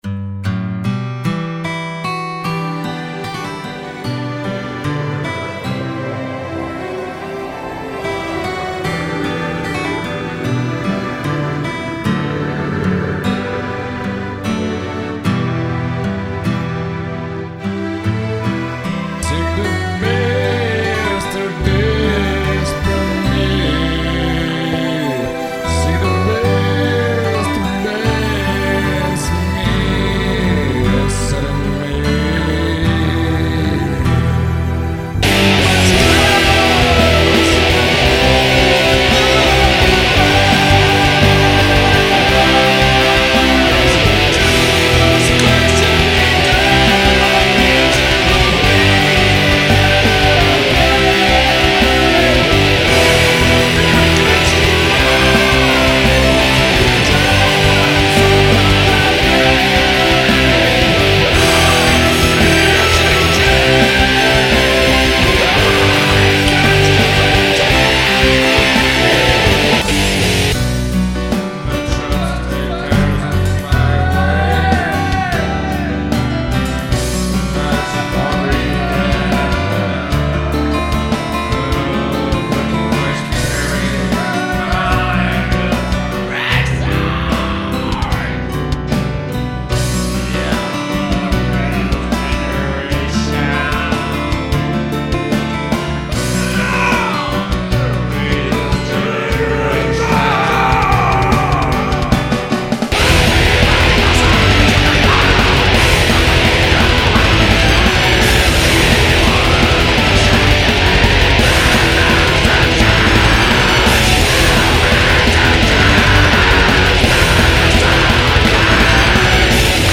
(Heavy Black Métal)